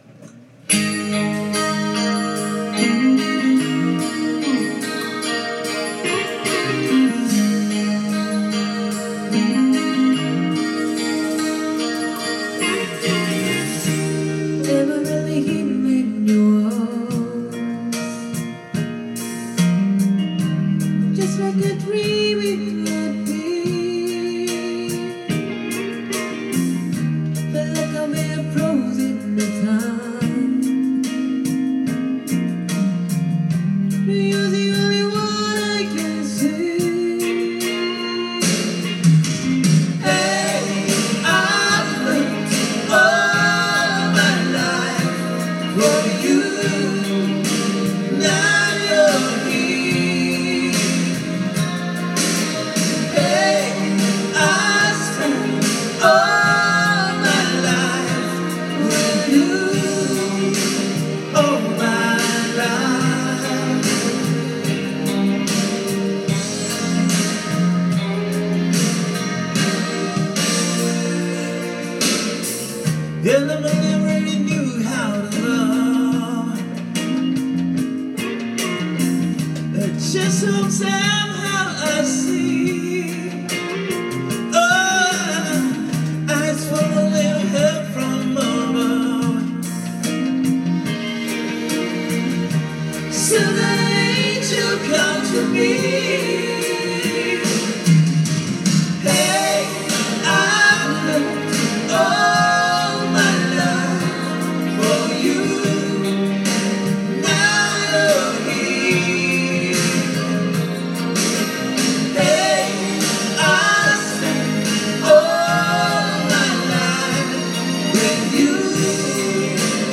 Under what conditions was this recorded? Duet & Chorus Night Vol. 13 TURN TABLE